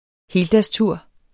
Udtale [ ˈheːldas- ]